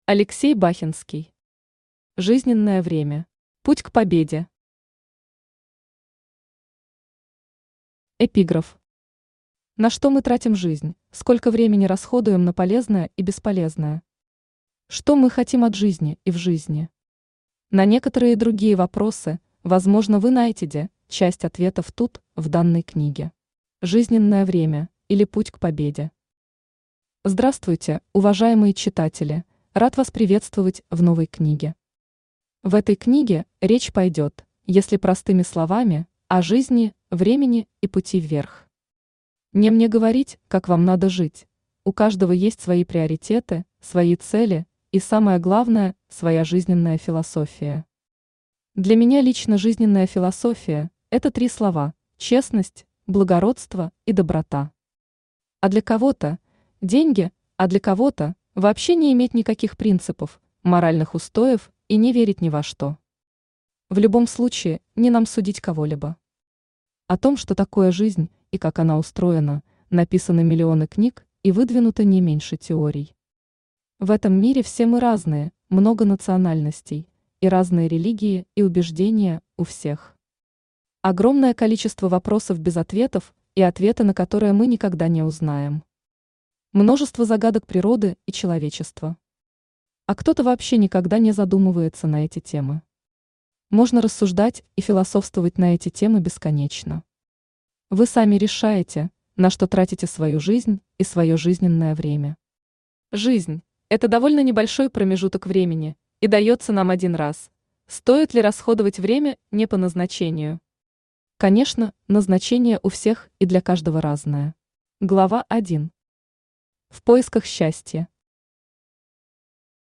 Аудиокнига Жизненное время. Путь к победе | Библиотека аудиокниг
Путь к победе Автор Алексей Бахенский Читает аудиокнигу Авточтец ЛитРес.